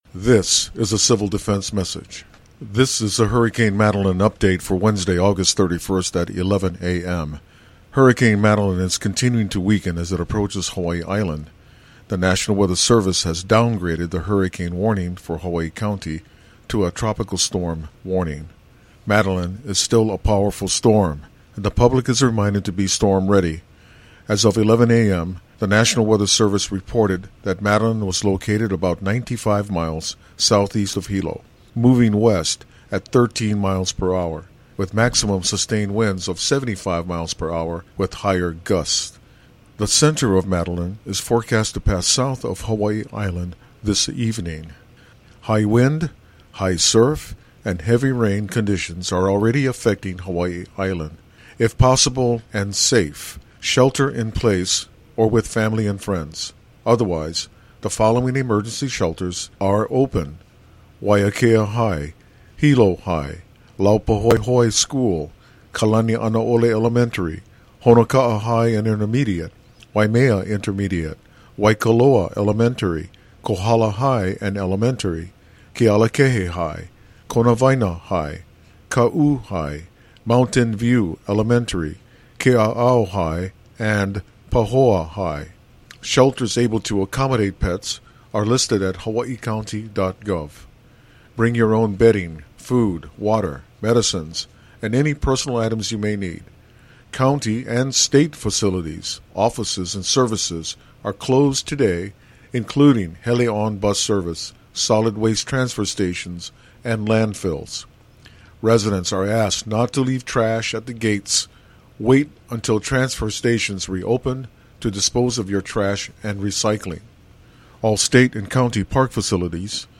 NOTE: The animation above was downloaded at 12:26 p.m. HST while the audio file beneath it is a revised Civil Defense message, more up-to-date than the one heard in the video at the top of the page. It includes an update on the full closure of Hawai‘i Volcanoes National Park as of noon today.